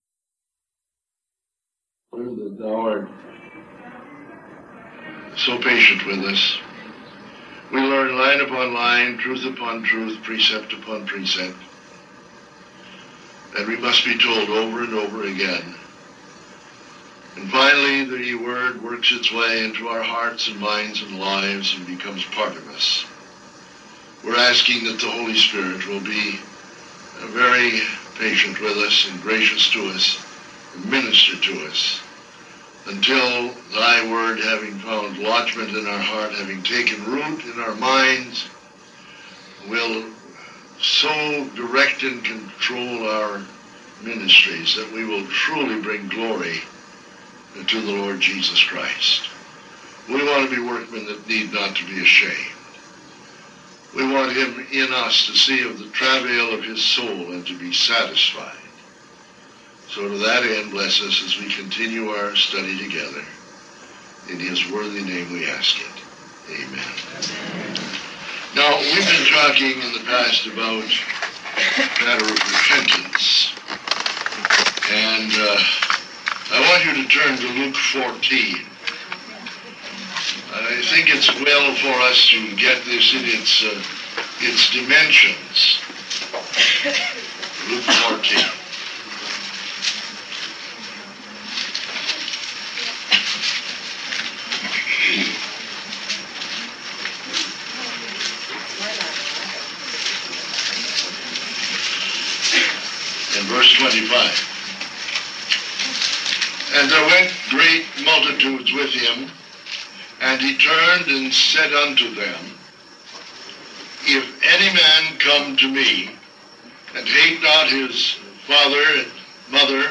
In this sermon, the preacher discusses the nature of sin and the commitment of the will to pleasing oneself. He then refers to a passage in Luke 14 where Jesus is invited to the house of a Pharisee and uses a parable to address the excuses people give for not repenting and receiving him. The three main excuses mentioned are family relationships, career ambitions, and possessions.